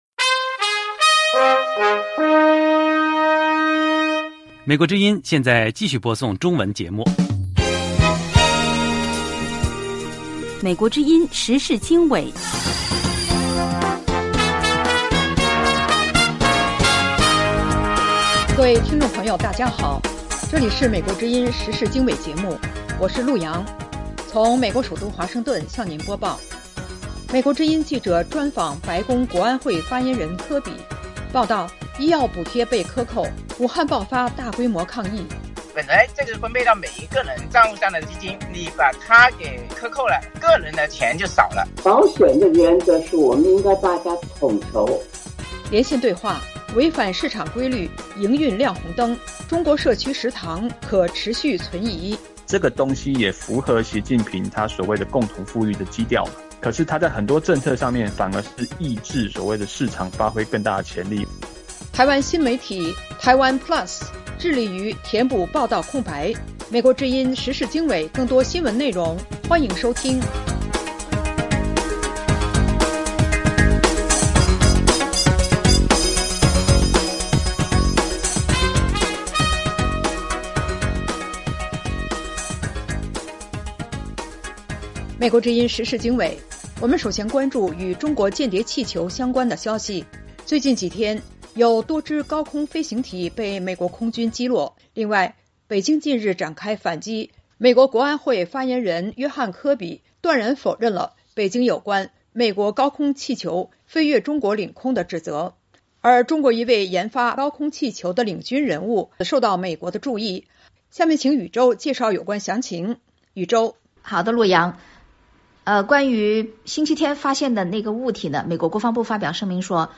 时事经纬(2023年2月14日)：1/美国之音记者专访白宫国安会发言人约翰·科比。2/报道：医药补贴被克扣 武汉爆发大规模抗议。3/连线对话：违反市场规律营运亮红灯 中国社区食堂可持续存疑。4/台湾新媒体TaiwanPlus致力于填补报道空白。